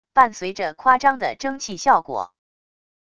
伴随着夸张的蒸汽效果wav音频